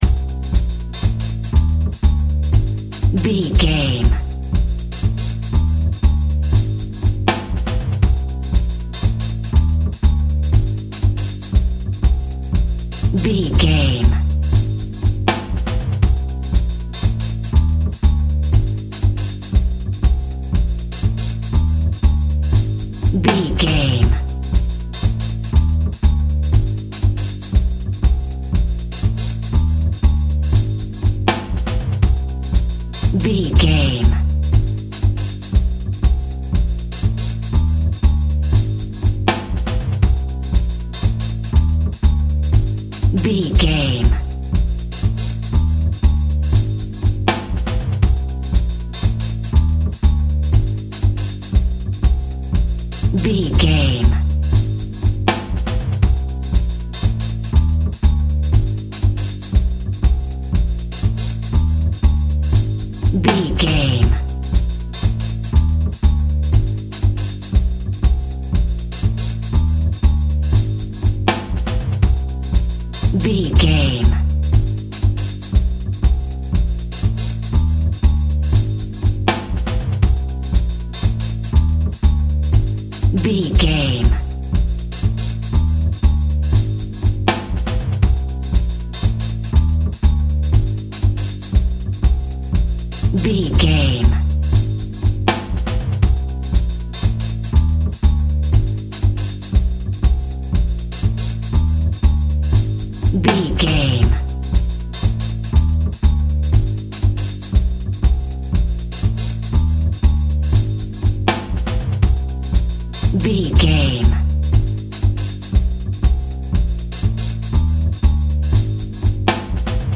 Aeolian/Minor
tension
ominous
suspense
eerie
drums
double bass
Funk
jazz
synth drums
synth bass